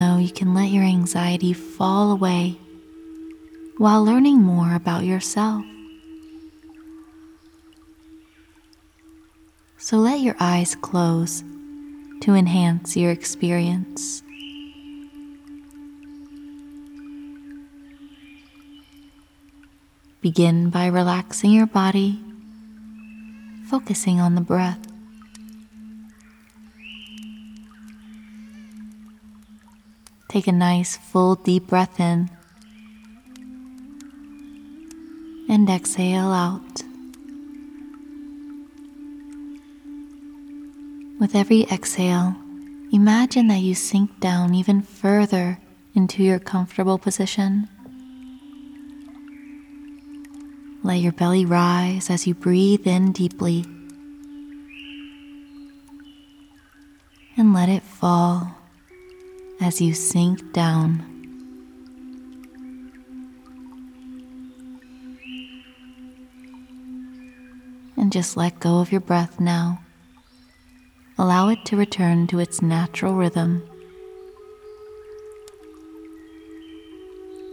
Audio Guided Meditation for Anxiety Relief